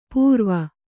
♪ pūrva